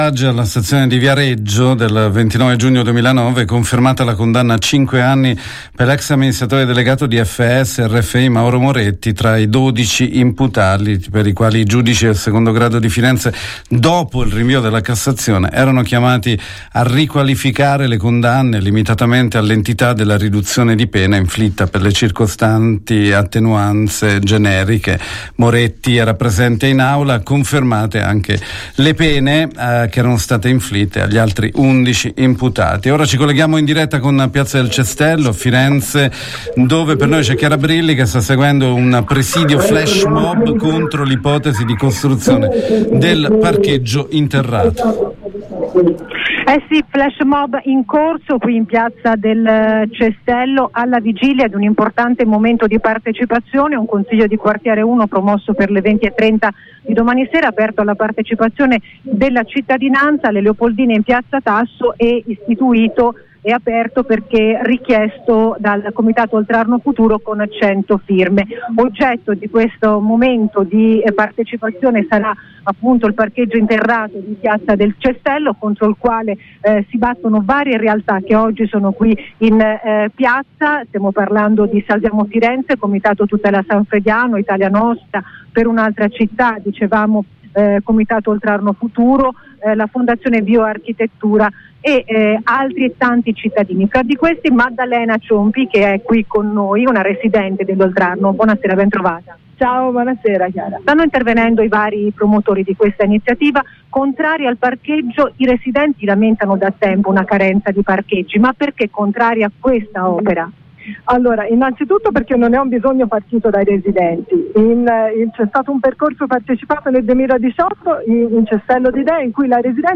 Parcheggio Cestello, flash mobileOggi pomeriggio flash mobile e presidio con tanto di pinne, machete e boccaglio. La diretta di